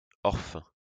Orphin (French pronunciation: [ɔʁfɛ̃]